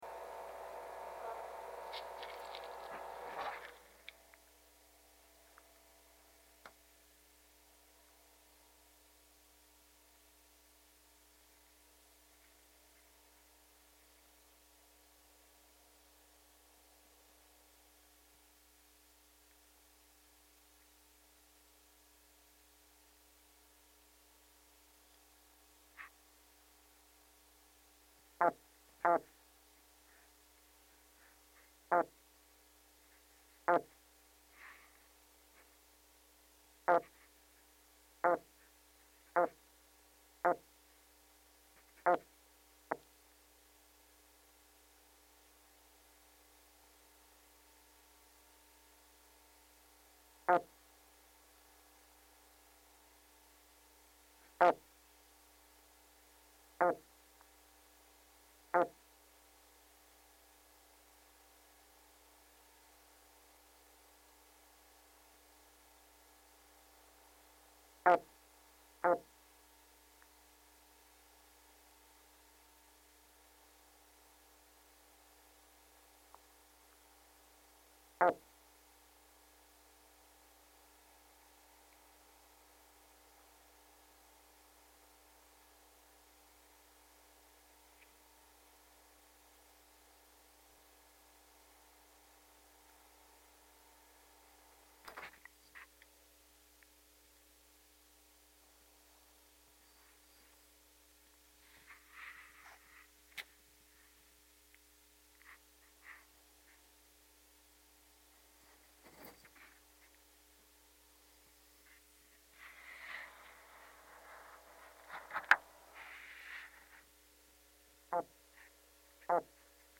花身鯻 Terapon jarbua
高雄市 鼓山區 哨船頭公園
錄音環境 保麗龍箱中